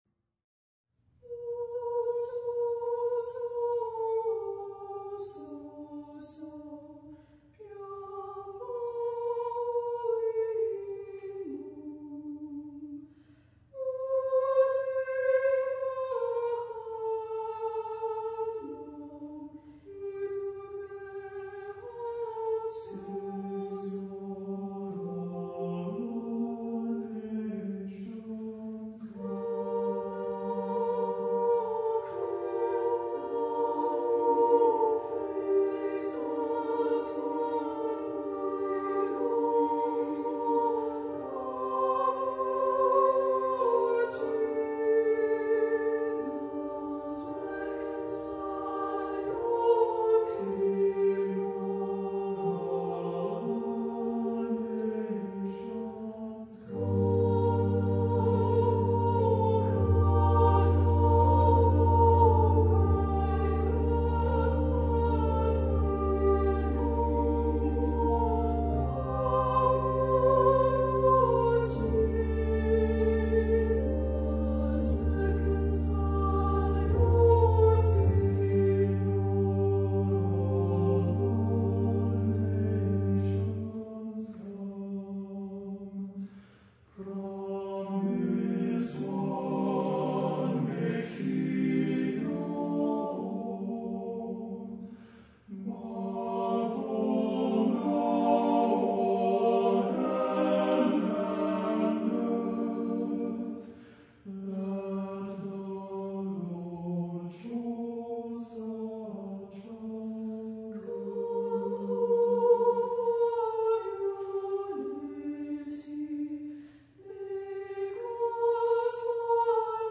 Voicing: 2-part Treble Voices, TB divisi